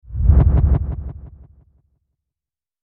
На этой странице собраны звуки, ассоциирующиеся с черной магией: загадочные шёпоты, ритуальные напевы, эхо древних заклинаний.
Звук применения черной магии